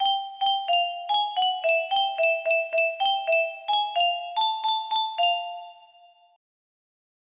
Music music box melody